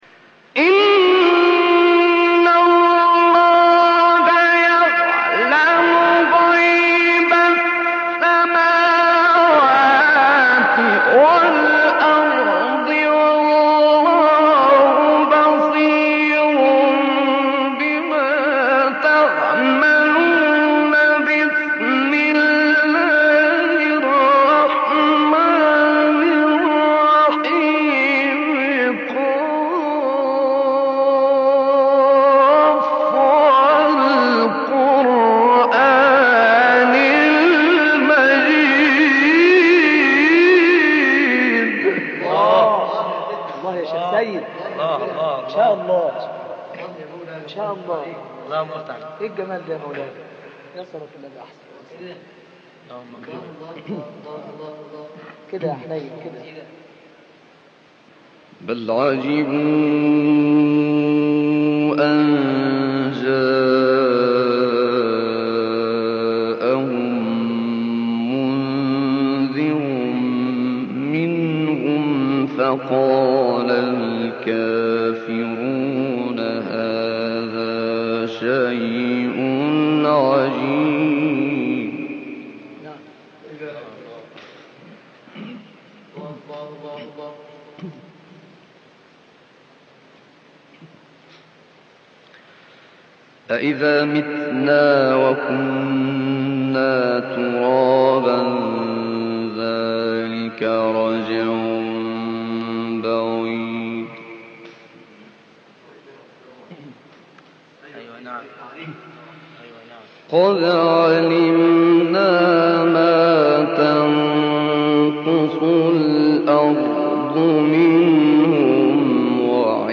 🔸اجرای استودیوئی در کشور مصر (همراه با تشویق نفرات محدود)
🔸در نغمه زیبای بیات